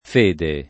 fede [ f % de ]